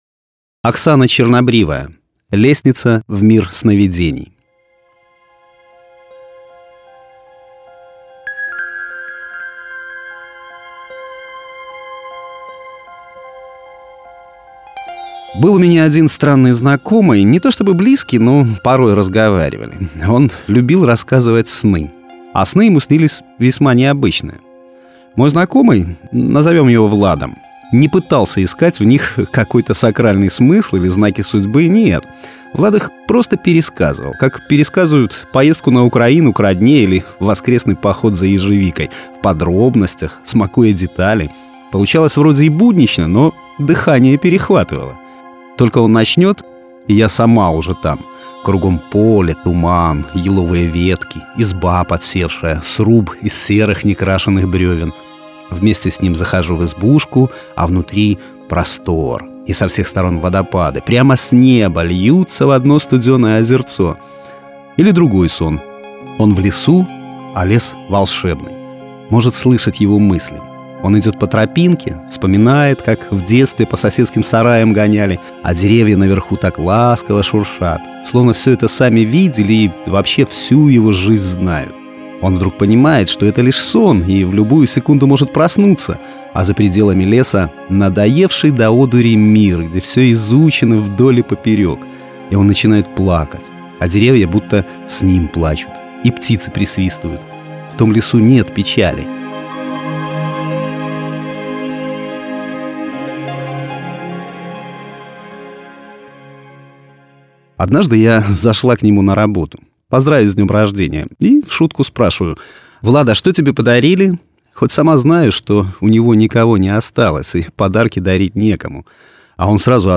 Аудио-Рассказы